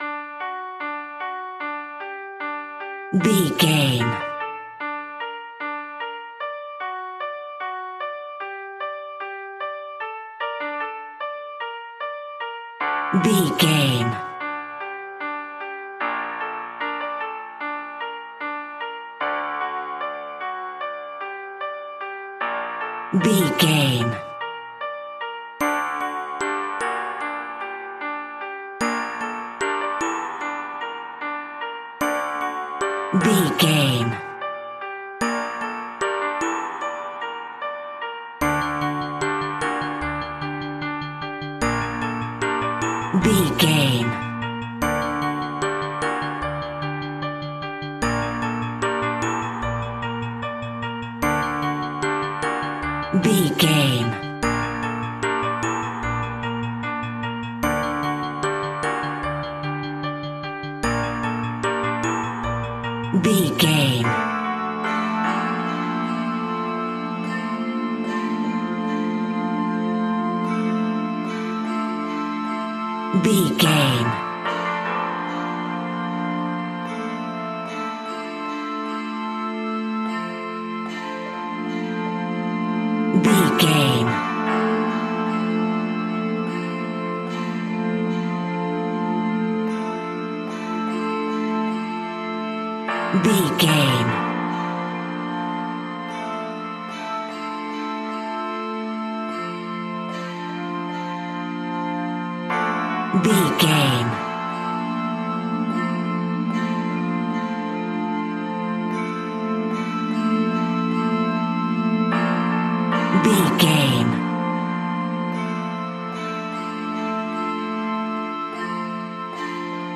Hammer Horror Music.
In-crescendo
Aeolian/Minor
scary
tension
ominous
dark
suspense
eerie
strings
synth
ambience
pads